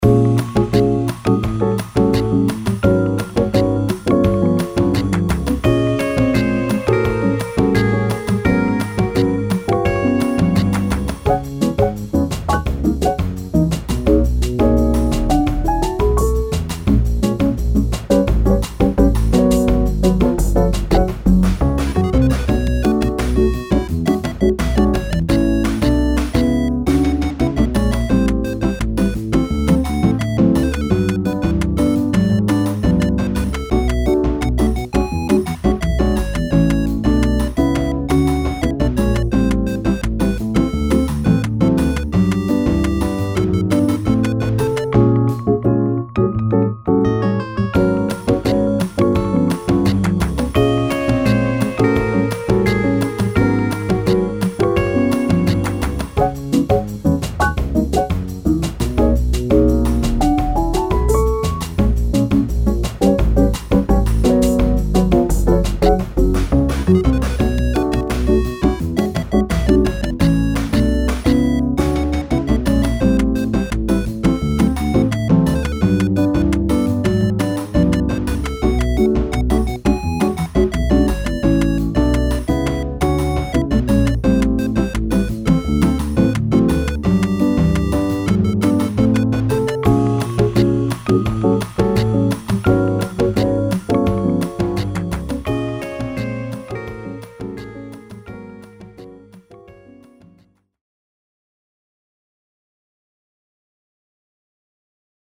あかるい おしゃれ かわいい FREE BGM